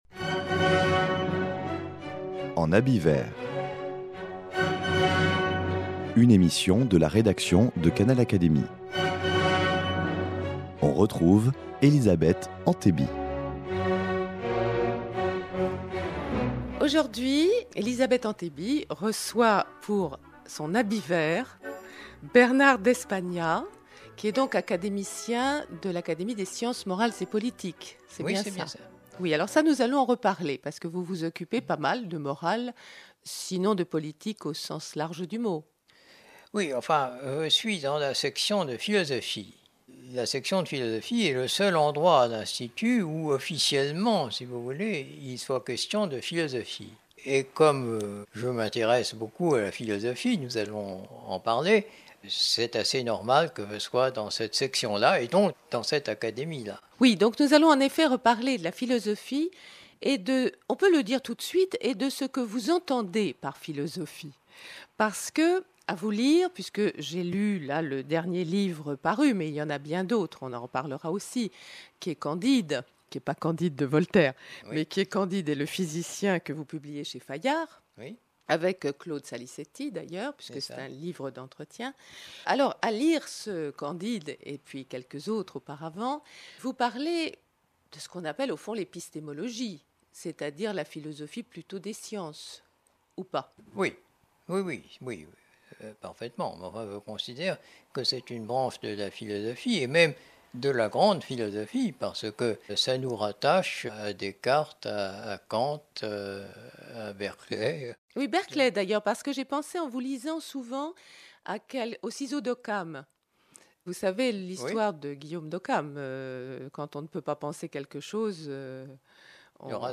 Bernard d’Espagnat nous reçoit à son domicile pour évoquer son parcours à la fois de physicien et de philosophe. Son point de vue sur la philosophie des sciences mérite qu’on s’y attarde.